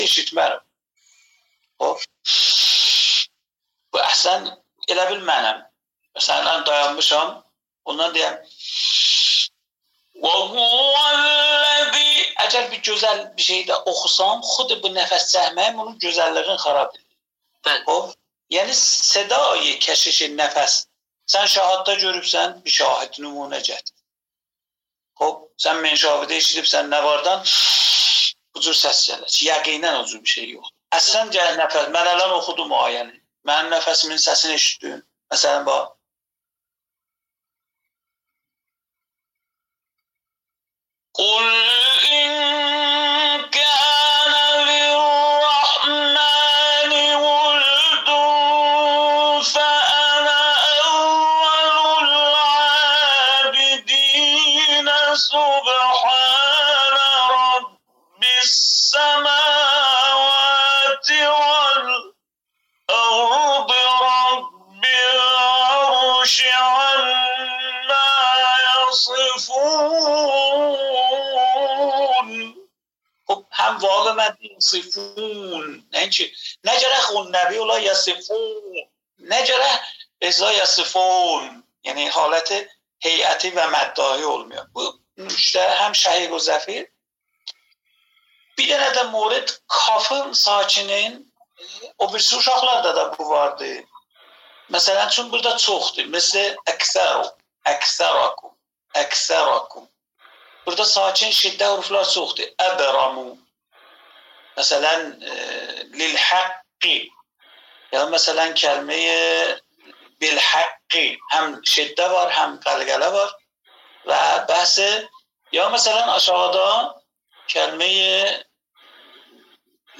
کارگاه آشنایی با راهکار‌های ارتقای صوت و لحن تخصصی قرآن کریم با موضوع «شهیق» و «زفیر» به صورت مجازی در اردبیل برگزار شد.